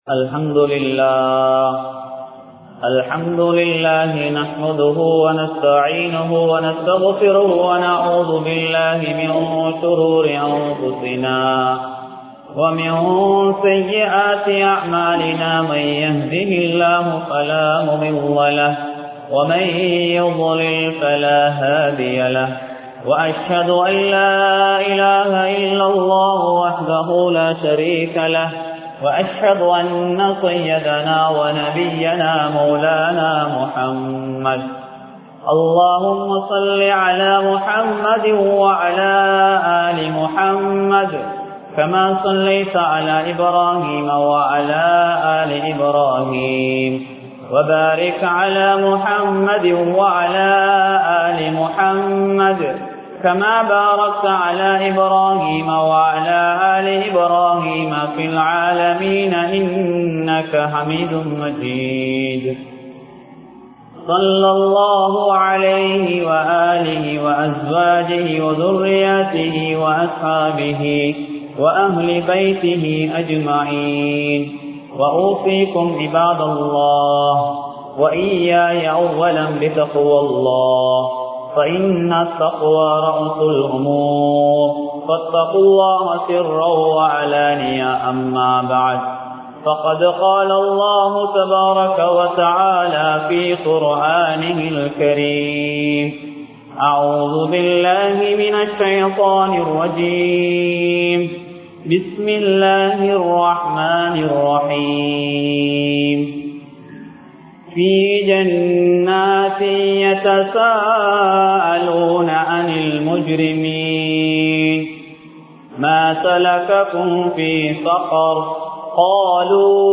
04 வினாக்கள் (04 Questions) | Audio Bayans | All Ceylon Muslim Youth Community | Addalaichenai
Puttalam, Thillayadi Aqsa Jumuah Masjith